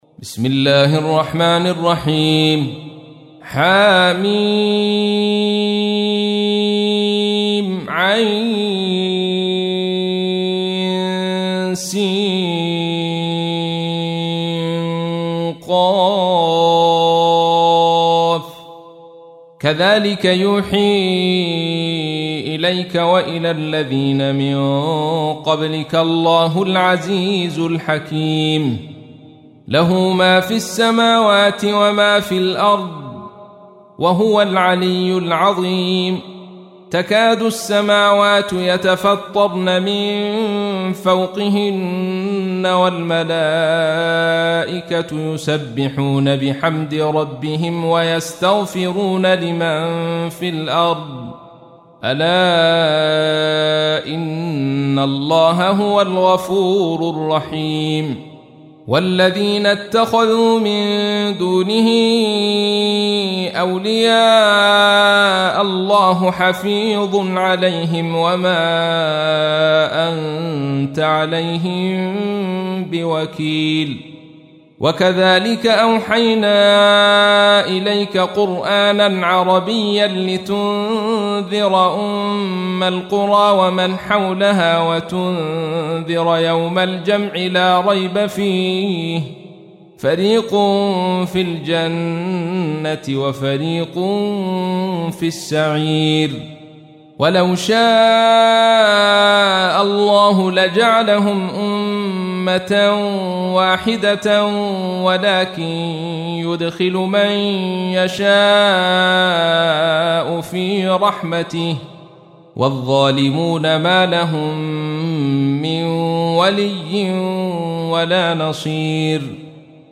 تحميل : 42. سورة الشورى / القارئ عبد الرشيد صوفي / القرآن الكريم / موقع يا حسين